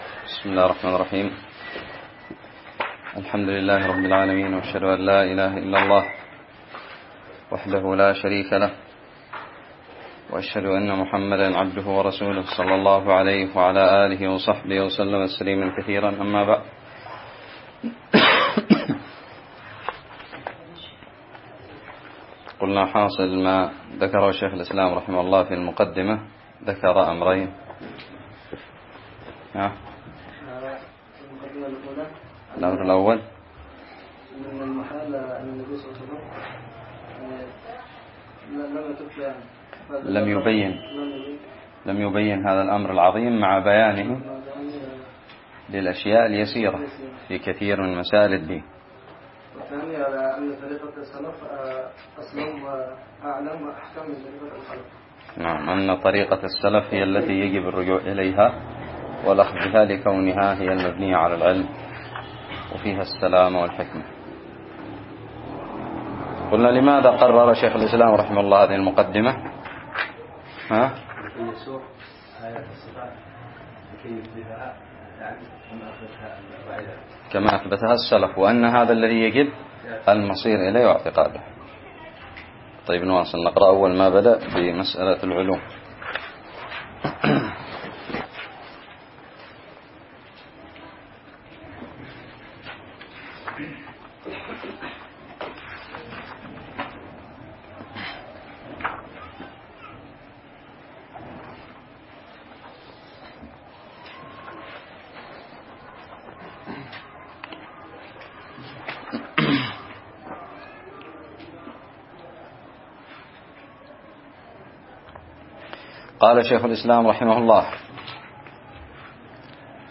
الدرس الثالث من شرح متن الحموية
ألقيت بدارالحديث السلفية للعلوم الشرعية بالضالع